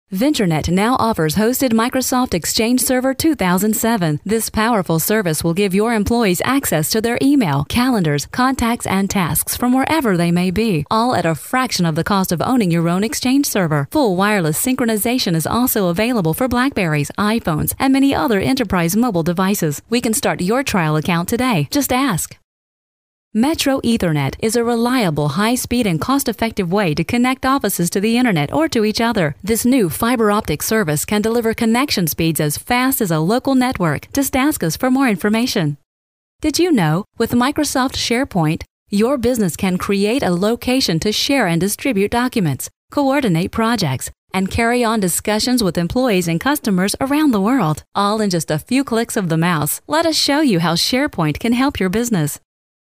American female voice over...warm, friendly, business
mid-atlantic
Sprechprobe: Industrie (Muttersprache):
Professional female voice over for muliple projects.